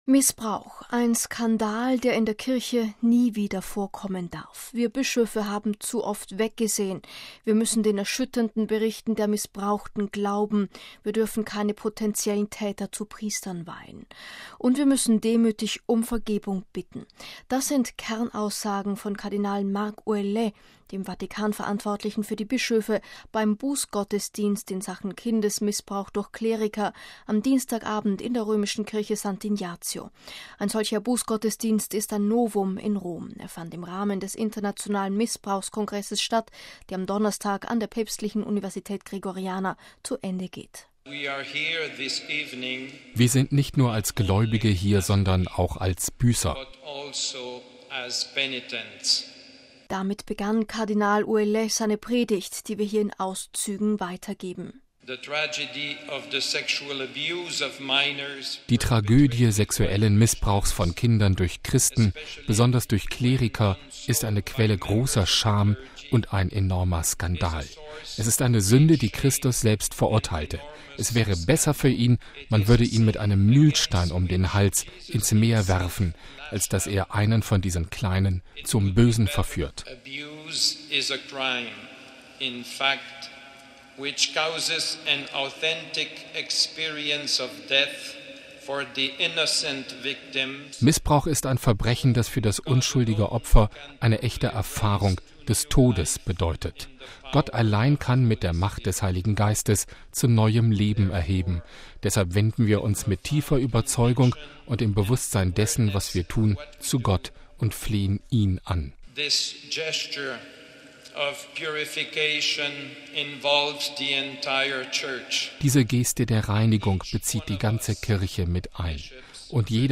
Das sind Kernaussagen von Kardinal Marc Ouellet, dem Vatikan-Verantwortlichen für die Bischöfe, beim Bußgottesdienst in Sachen Kindesmissbrauch durch Kleriker Dienstagabend in der römischen Kirche St. Ignatius.
„Wir sind nicht nur als Gläubige hier, sondern auch als Büßer“, stellte Kardinal Ouellet zu Beginn seiner Predigt klar, die wir hier auszugsweise wiedergeben.